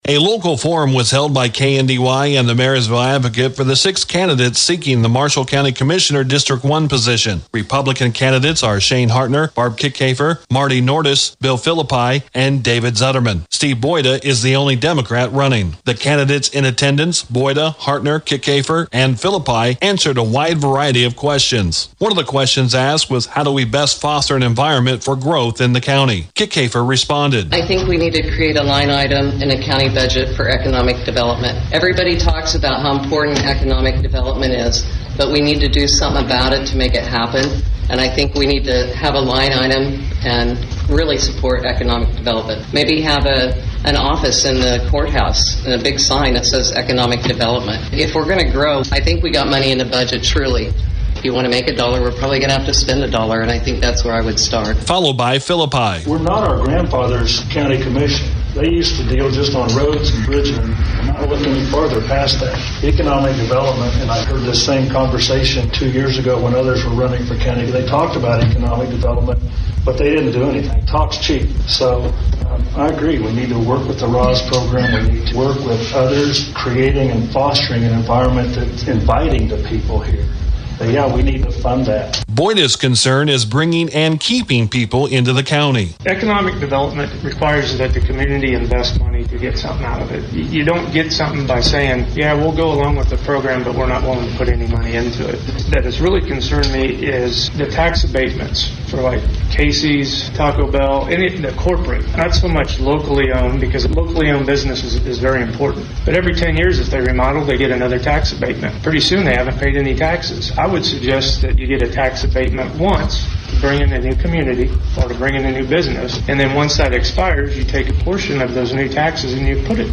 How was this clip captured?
KNDY along with The Marysville Advocate held a Candidate Forum for the 2018 primary election. Hear what the candidates had to say about some of the local topics of interest.